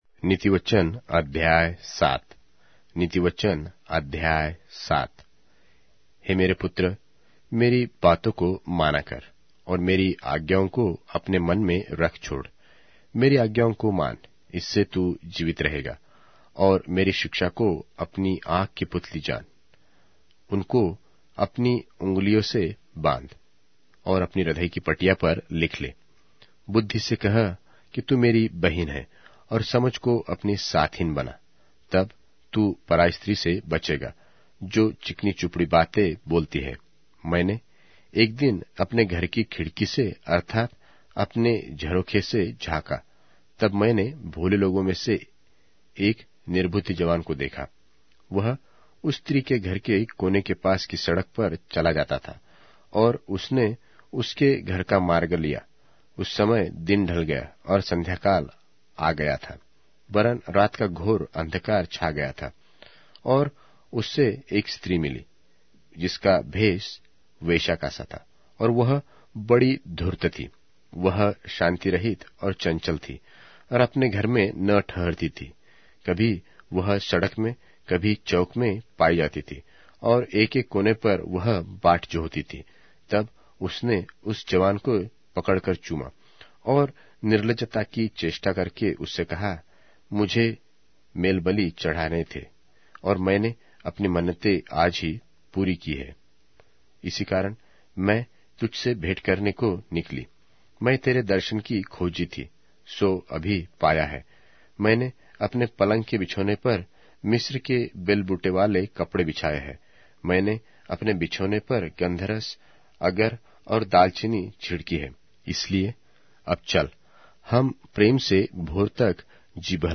Hindi Audio Bible - Proverbs 28 in Tev bible version